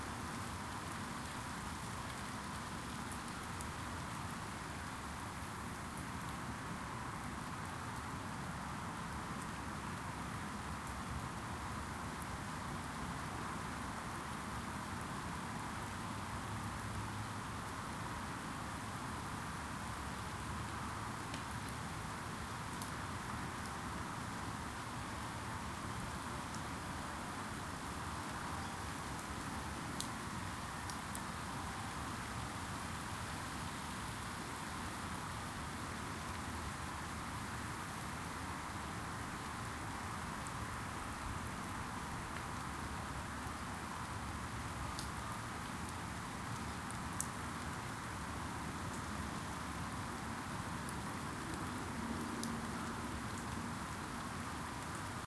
drizzleLoop.ogg